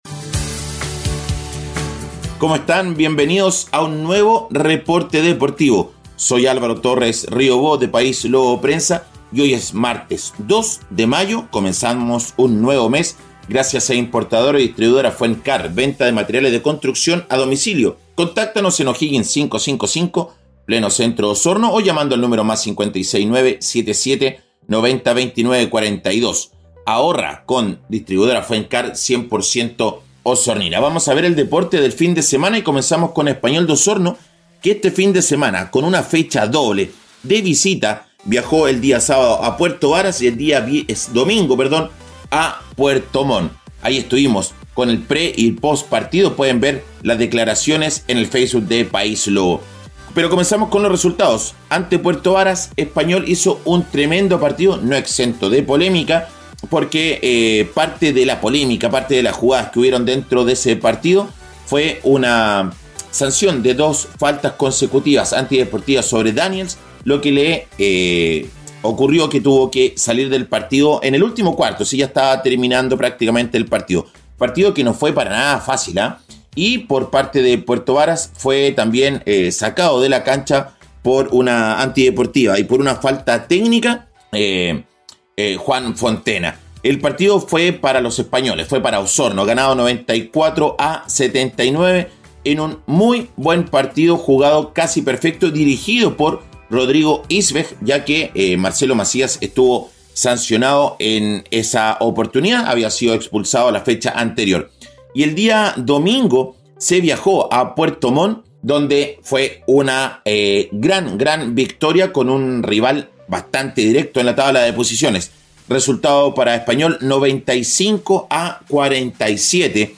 Reporte Deportivo 🎙 Podcast 02 de mayo de 2023